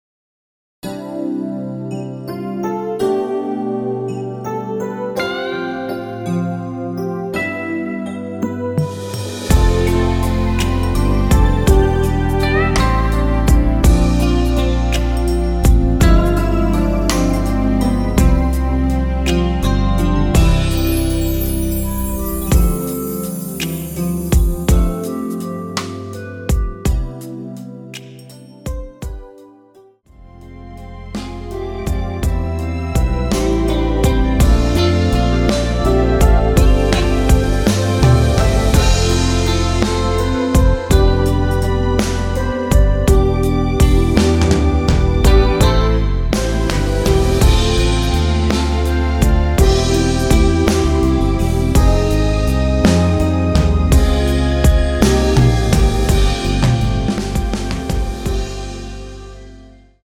원키에서(-2)내린 (2절 삭제) 멜로디 포함된 MR입니다.(미리듣기 참조)
앞부분30초, 뒷부분30초씩 편집해서 올려 드리고 있습니다.
중간에 음이 끈어지고 다시 나오는 이유는